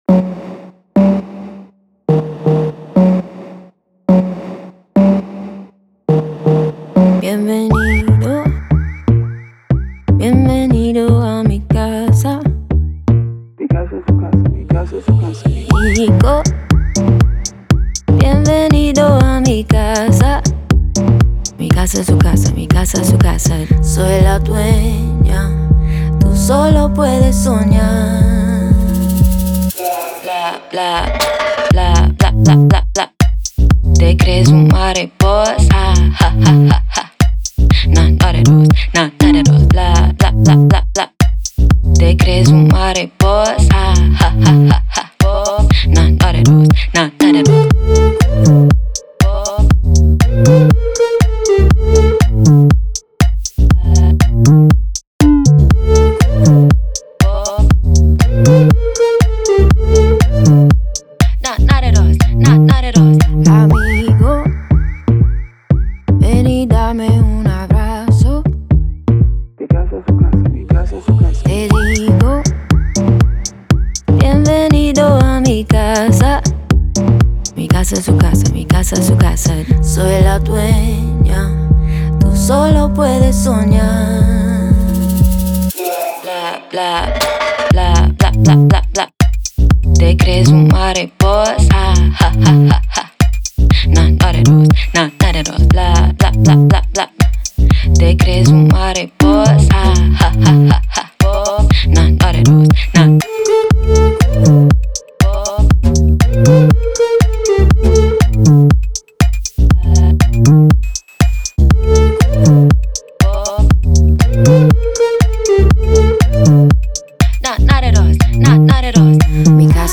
демонстрирует мощный вокал, который подчеркивает её харизму